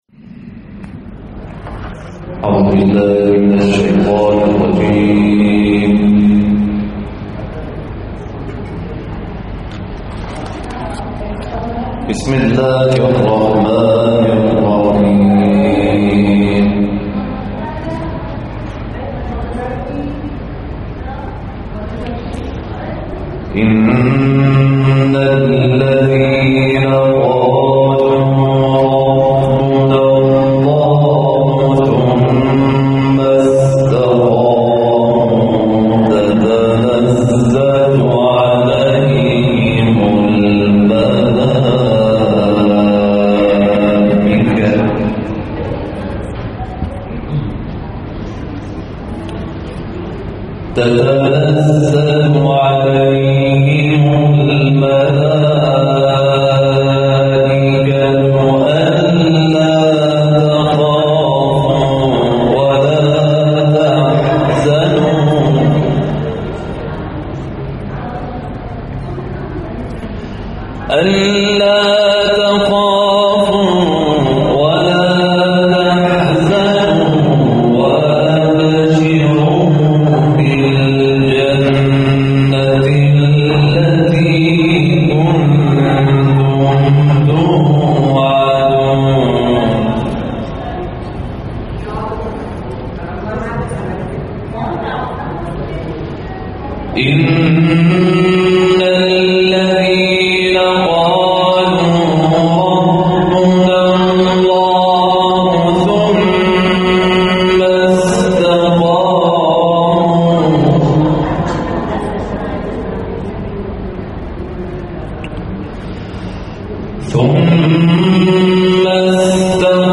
این تلاوت کوتاه در مسجد امام علی النقی(ع) امیرآباد شمالی در بهمن ماه اجرا شده است.